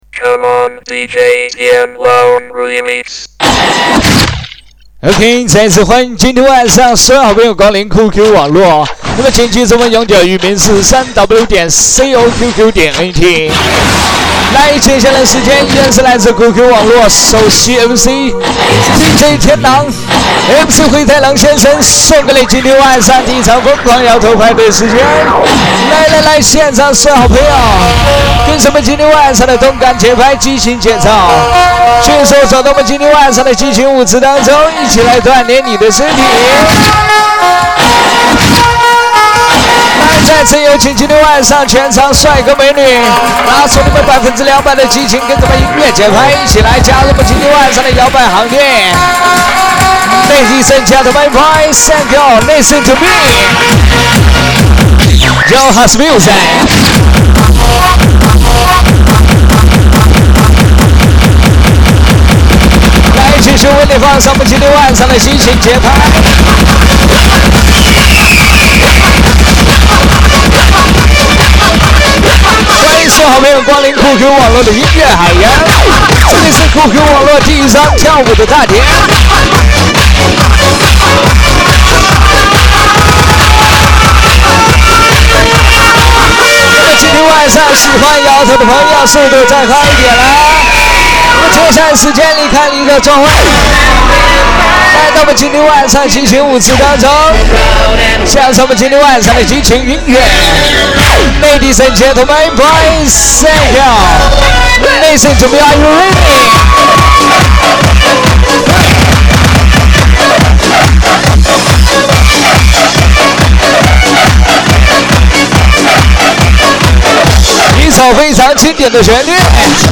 [MC喊麦]